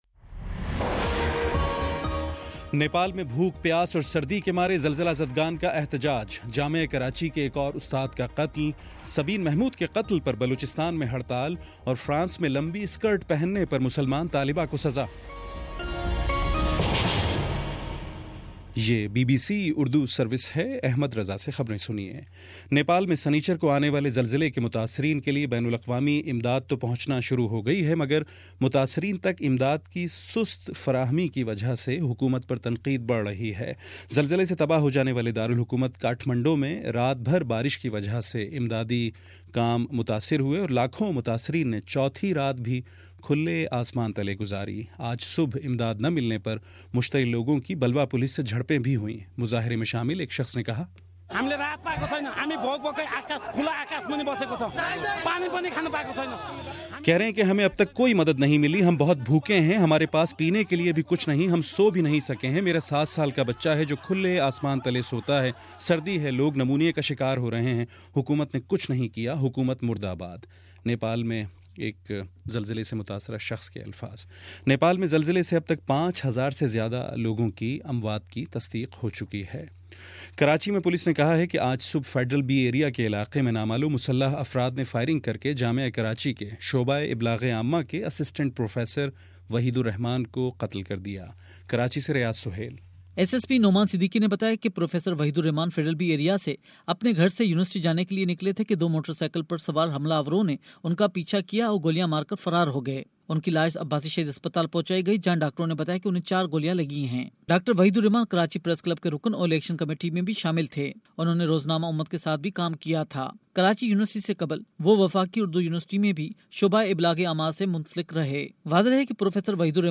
اپریل29: شام پانچ بجے کا نیوز بُلیٹن